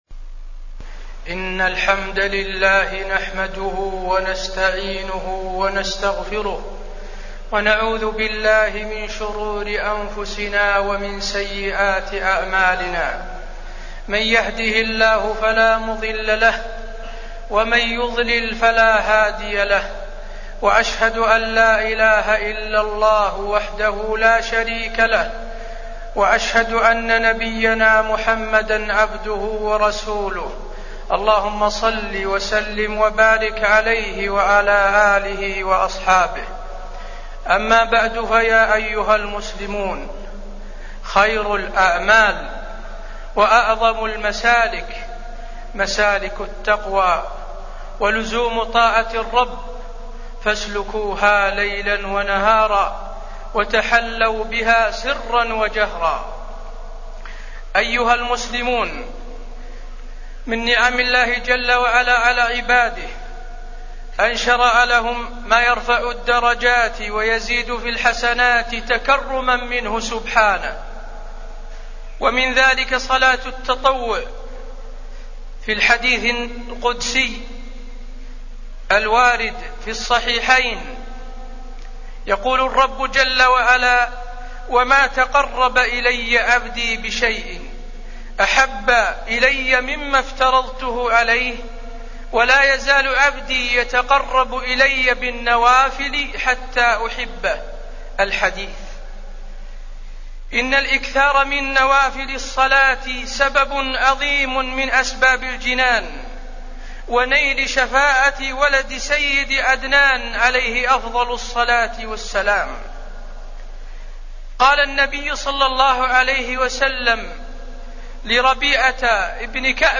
تاريخ النشر ١٠ شوال ١٤٢٩ هـ المكان: المسجد النبوي الشيخ: فضيلة الشيخ د. حسين بن عبدالعزيز آل الشيخ فضيلة الشيخ د. حسين بن عبدالعزيز آل الشيخ أحكام الوتر